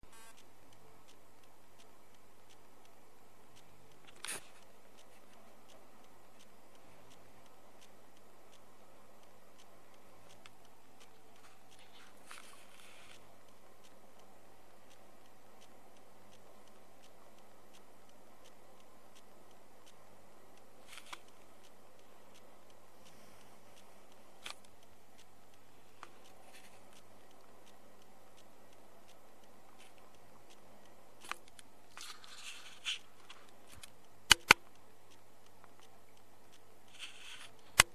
2011世界电梯大会现场录音